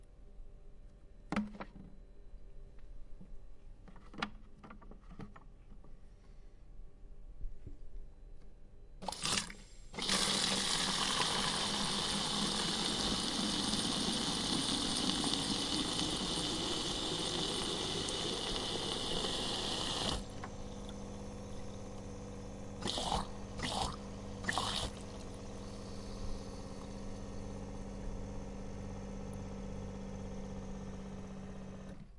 水龙头注水花瓶
描述：用浴缸龙头的水填充花瓶。
标签： 填充 水槽 填充起来 填充式 填充 玻璃 花瓶 液体 水龙头
声道立体声